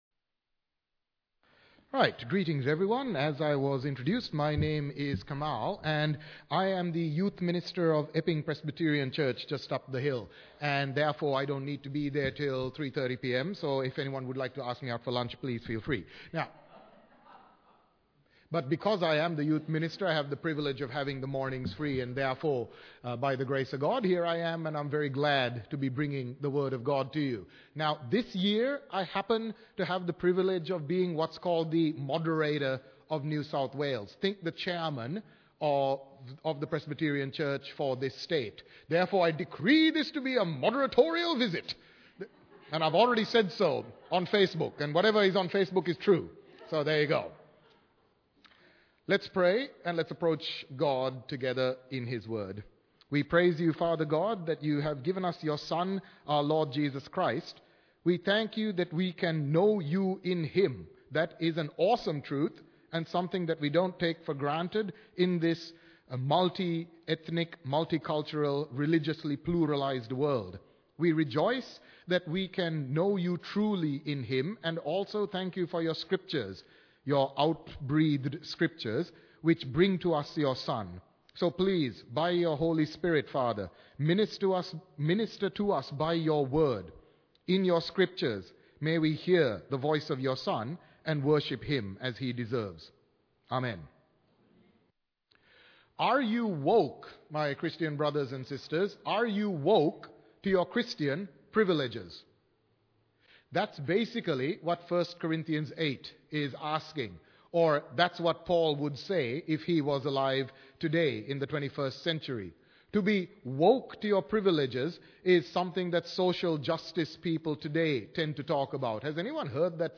Bible Text: 1 Corinthians 8 | Preacher